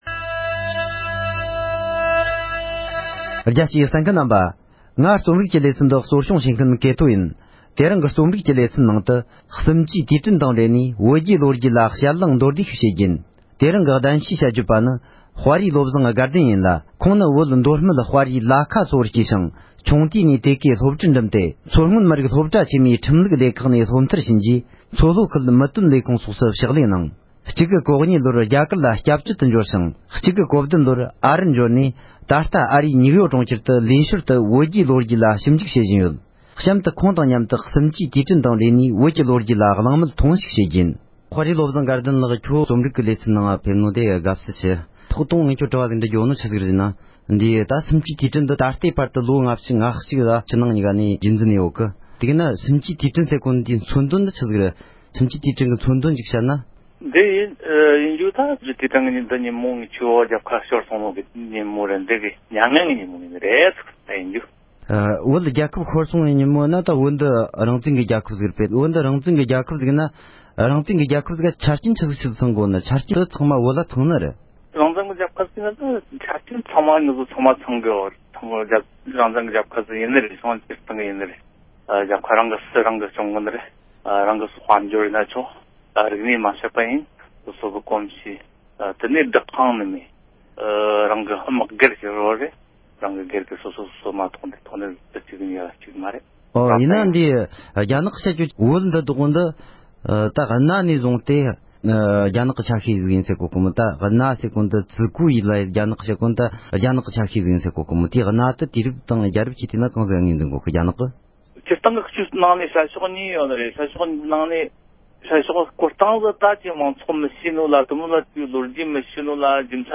བོད་རྒྱའི་ལོ་རྒྱུས་སྐོར་གླེང་མོལ་ཞུས་པའི་དུམ་བུ་དང་པོ།